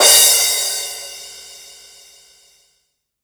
• Big Room Crash Cymbal Audio Clip C# Key 12.wav
Royality free crash cymbal sound sample tuned to the C# note. Loudest frequency: 6909Hz
big-room-crash-cymbal-audio-clip-c-sharp-key-12-HCY.wav